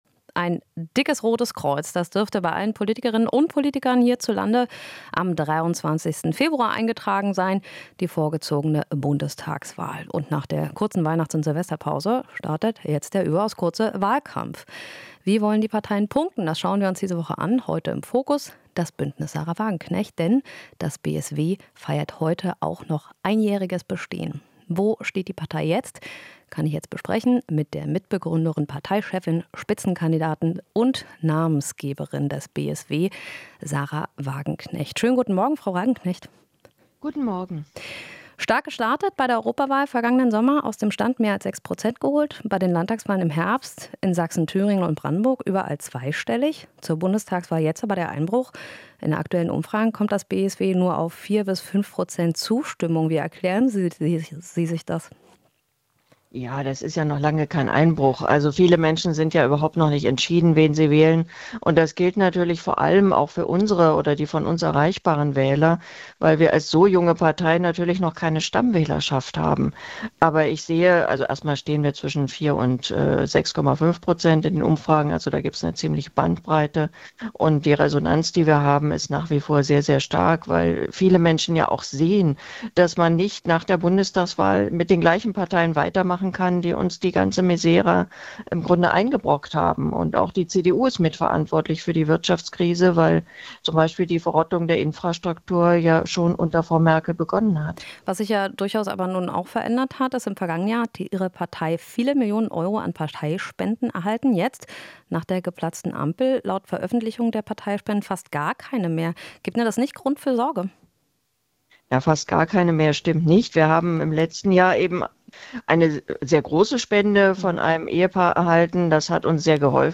Interview - Wagenknecht (BSW): "Der Weltfrieden ist in sehr großer Gefahr"